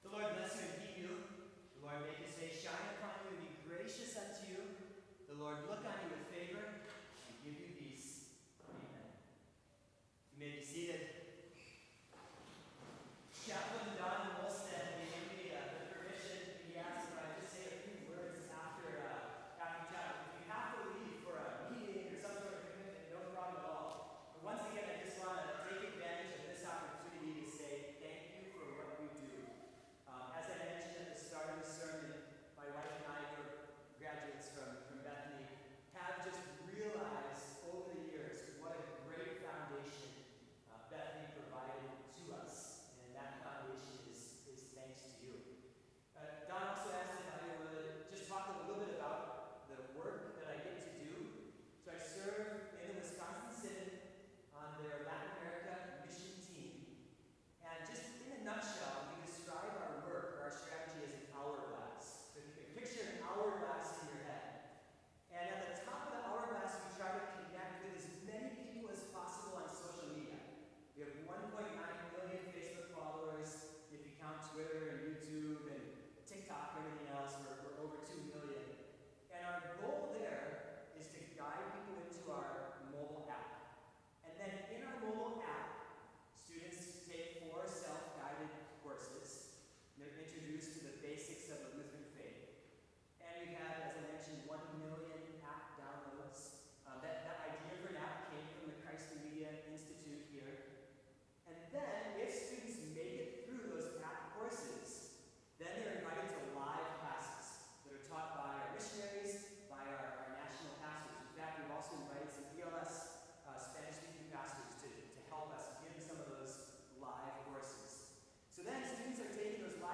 Sermon Only
This Chapel Service was held in Trinity Chapel at Bethany Lutheran College on Wednesday, July 5, 2023, at 10 a.m. Page and hymn numbers are from the Evangelical Lutheran Hymnary.